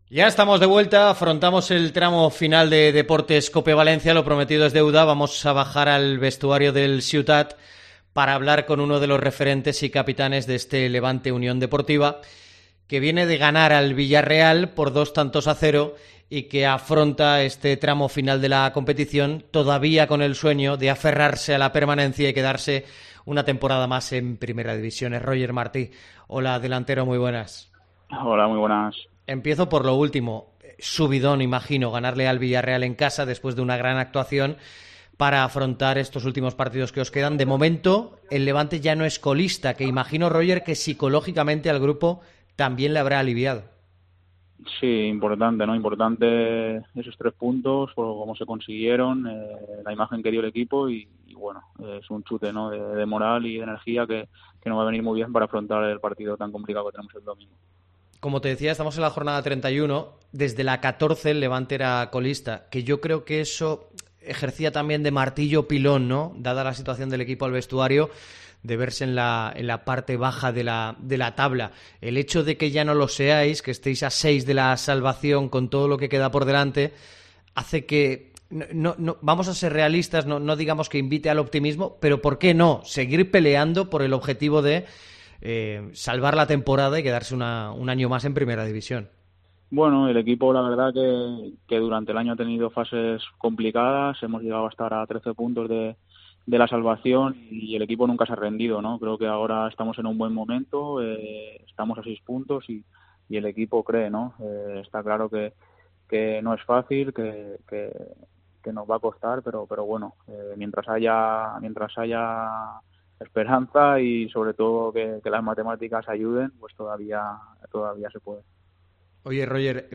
El delantero del Levante UD atiende a Deportes COPE Valencia y repasa la dura temporada. Roger cree en la salvación y se ve en Primera de azulgrana
Entrevista COPE a Roger Martí